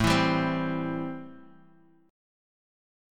Am7 Chord
Listen to Am7 strummed